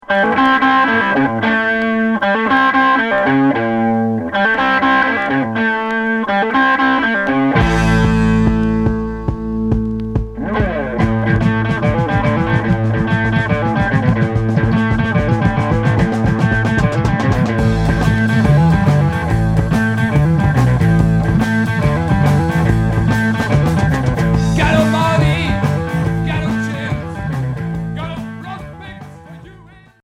Heavy rock